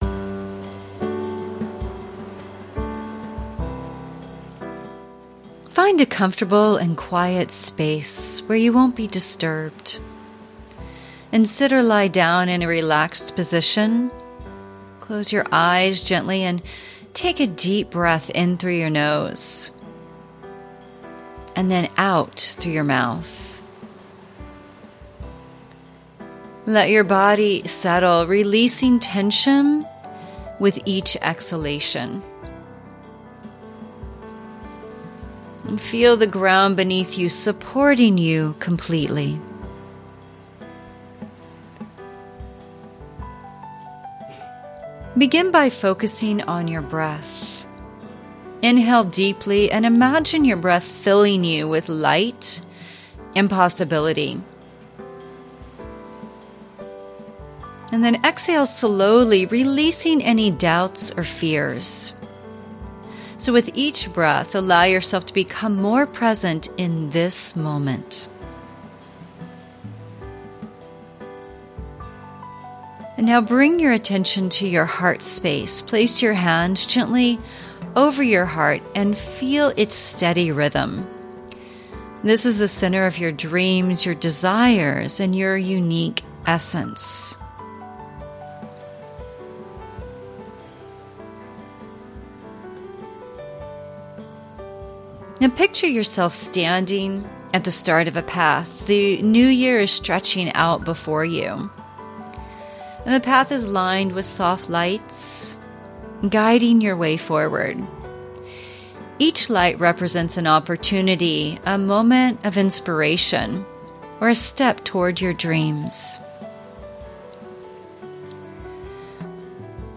Private Guided Meditation
a guided meditation on dreams.